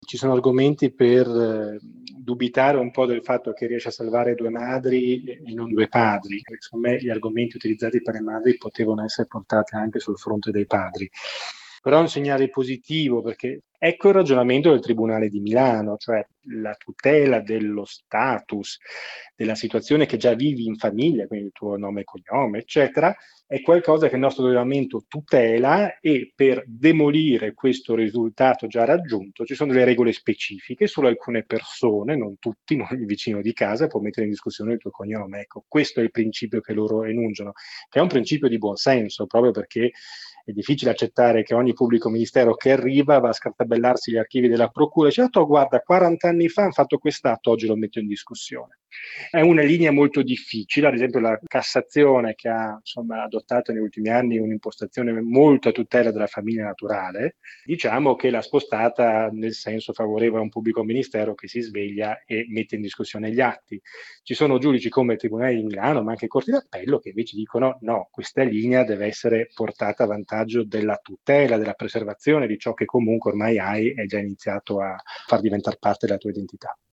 Gli abbiamo chiesto un commento sulla decisione del tribunale di Milano